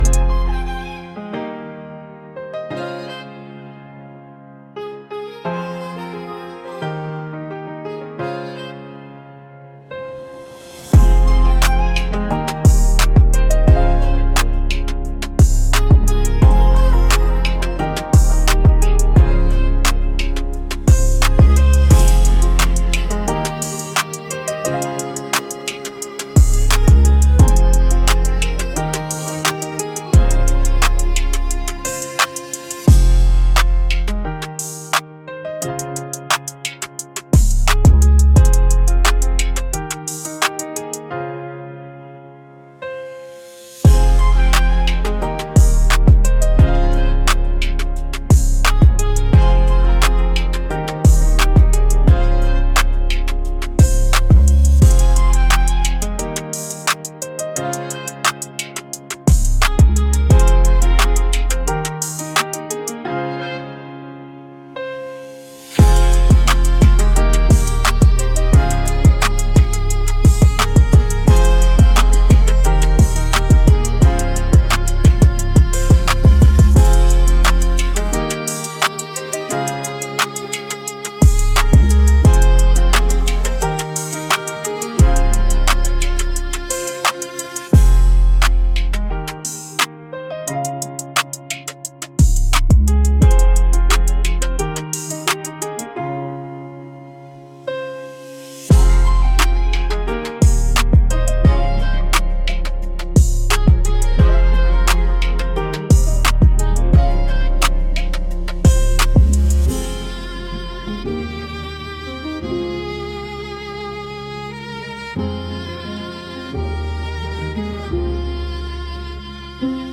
🎤 Rap / Hip-Hop
Rap • Harmonizing